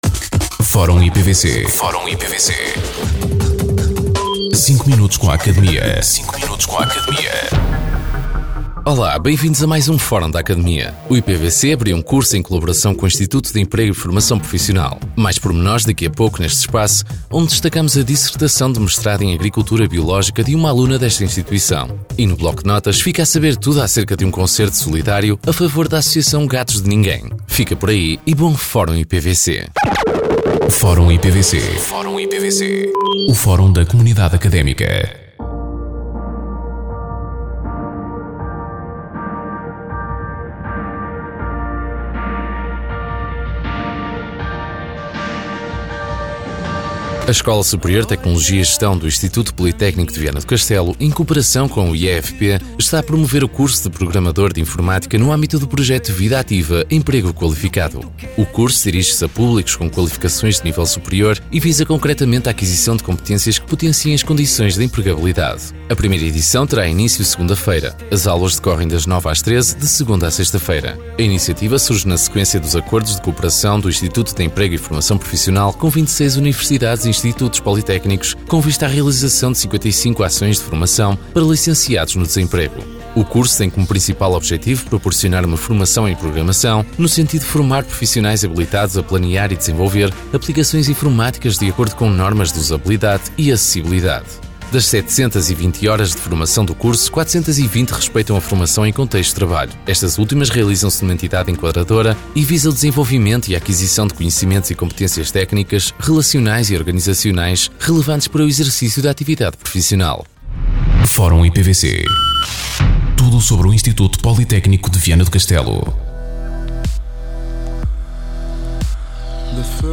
Depois do programa “Academia”, surge assim o “Fórum IPVC”, uma rubrica semanal de cinco minutos, em que são abordadas as notícias e factos mais relevantes da nossa instituição.
Entrevistados: